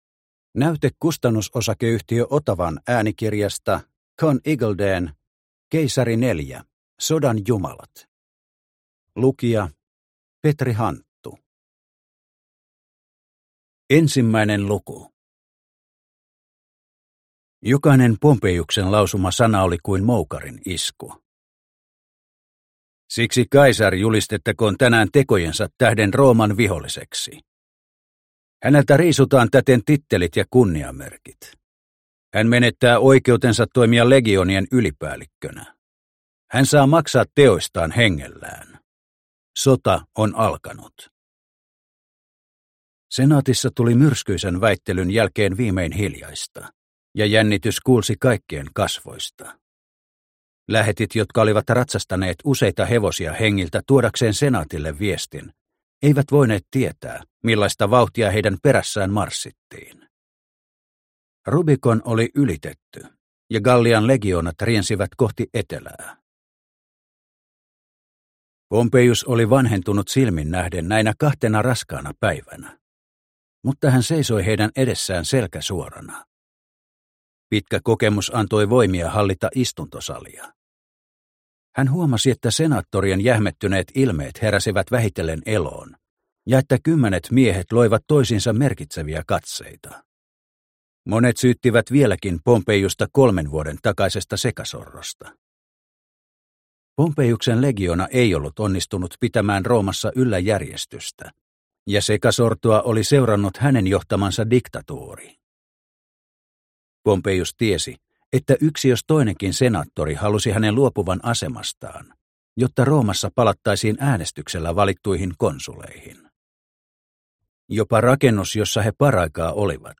Keisari IV. Sodan jumalat – Ljudbok – Laddas ner